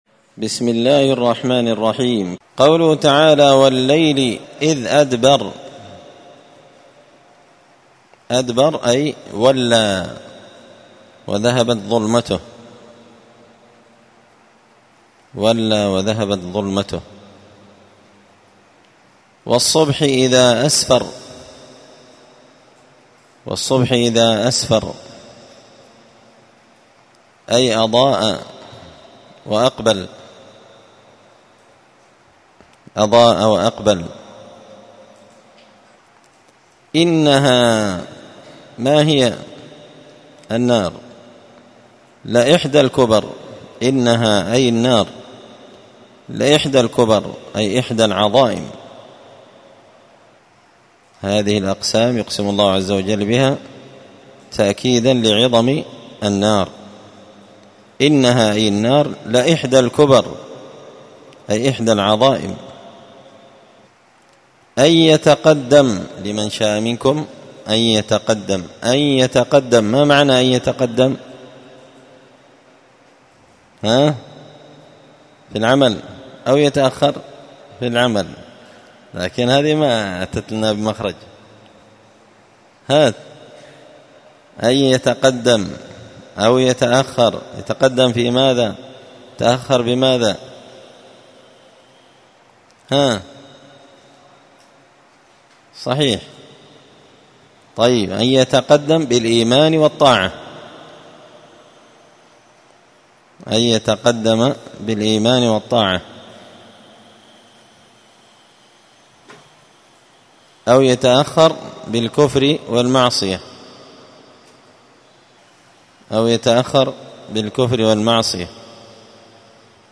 الثلاثاء 20 صفر 1445 هــــ | الدروس، دروس القران وعلومة، زبدة الأقوال في غريب كلام المتعال | شارك بتعليقك | 16 المشاهدات